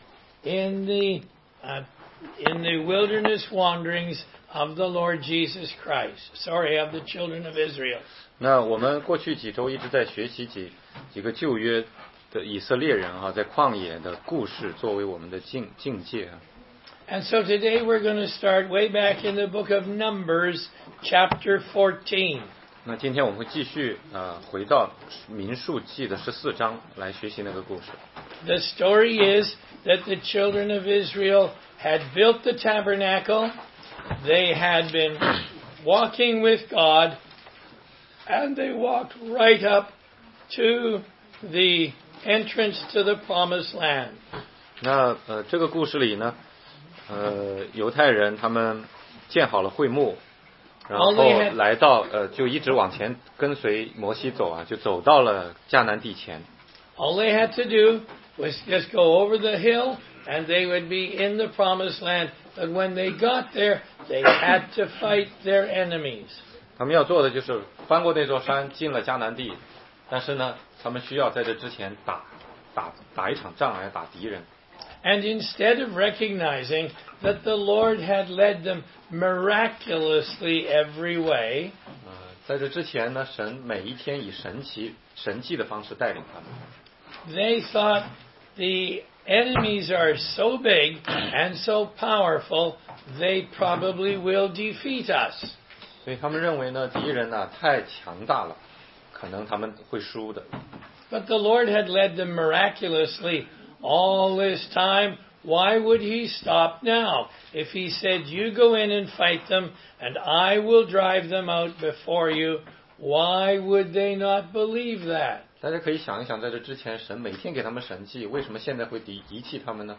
16街讲道录音 - 哥林多前书10章6-14节：旷野的警告之五